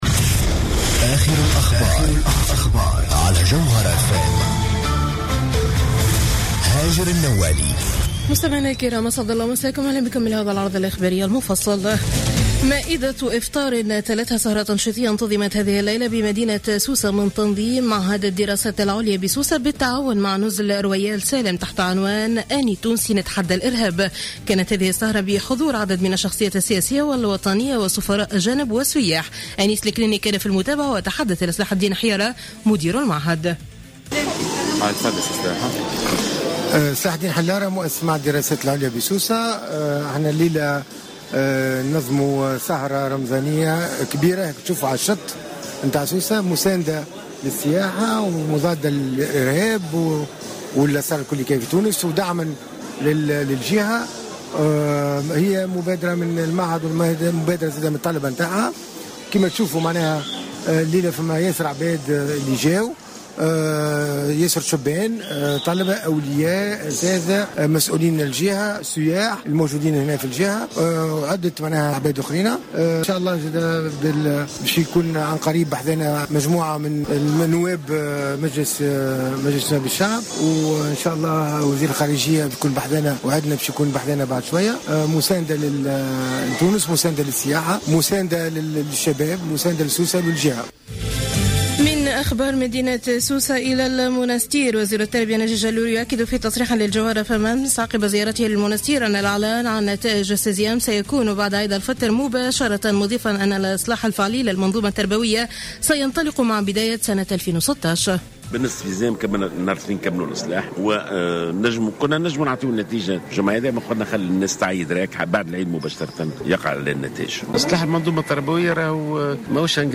نشرة أخبار منتصف الليل ليوم الأحد 12 جويلية 2015